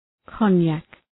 Προφορά
{‘kəʋnjæk}